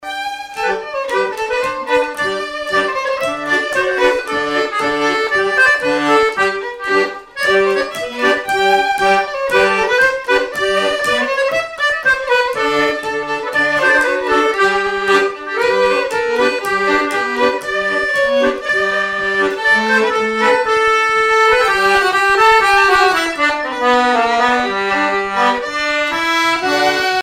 Marche
Miquelon-Langlade
danse : marche
violon
Pièce musicale inédite